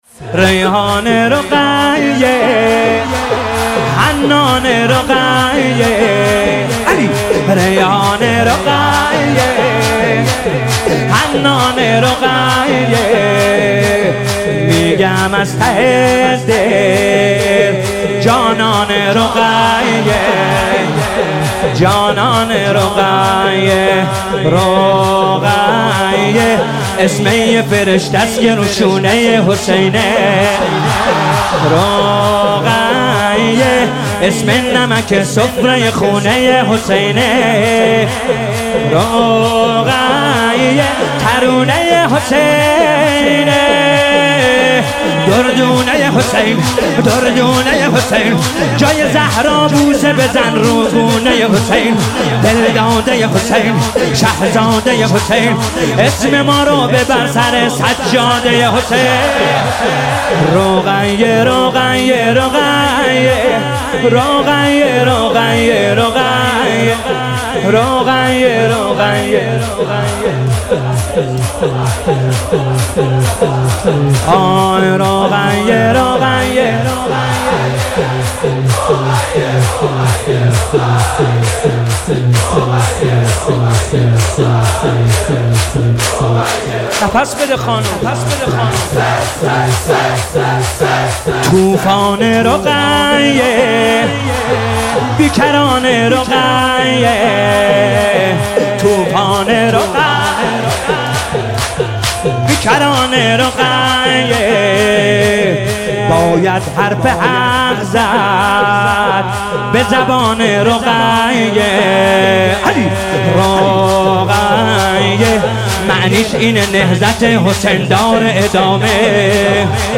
با نوای دلنشین
به مناسبت شهادت حضرت رقیه سلام الله علیها